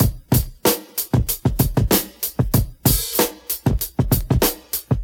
R&B Drum Loop Free sound effects and audio clips
Original creative-commons licensed sounds for DJ's and music producers, recorded with high quality studio microphones.
85 bpm A groovy drum beat.wav
A funky drum loop with a nice swing to it. This could fit in many urban style tracks (hip-hop, rnb etc.). The bass drum is tuned in A.